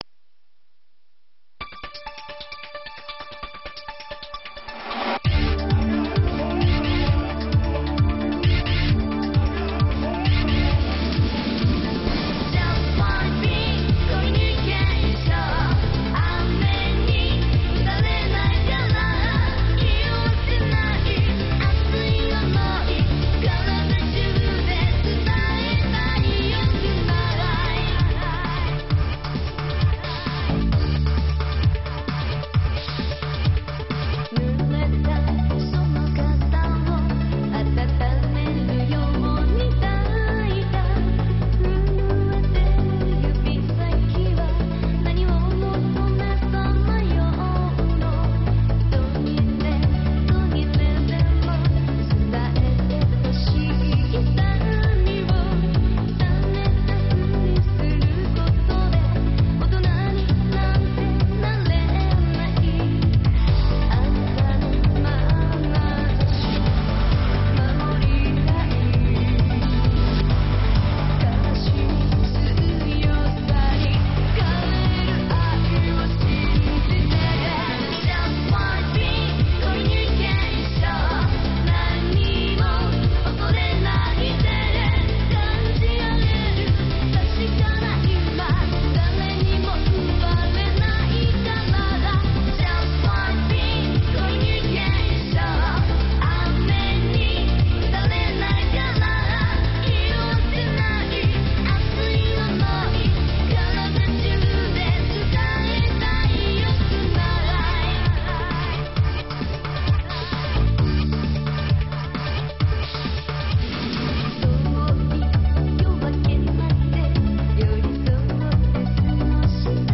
32Kbps, 44khz, Mono